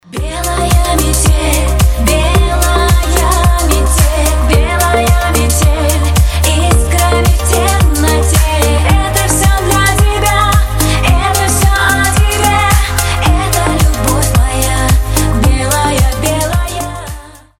• Качество: 320, Stereo
поп
громкие
женский вокал
dance
club